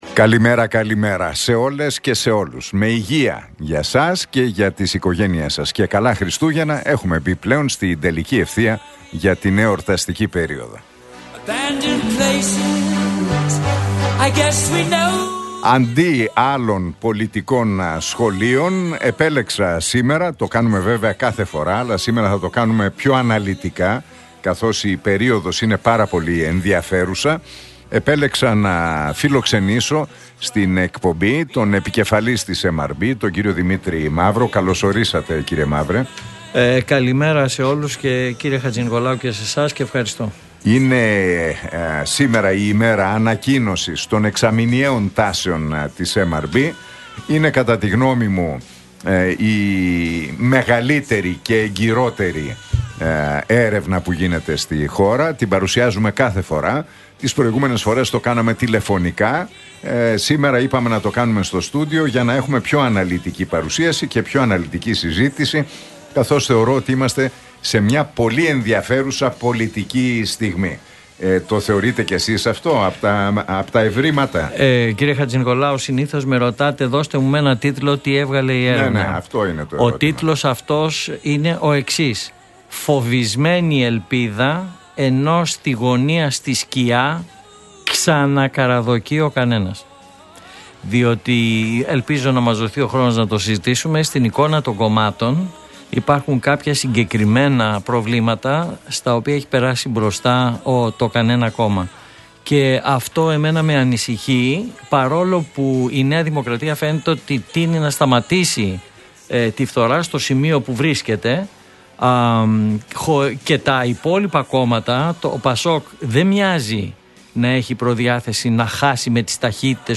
μιλώντας στον Realfm 97,8 και την εκπομπή του Νίκου Χατζηνικολάου.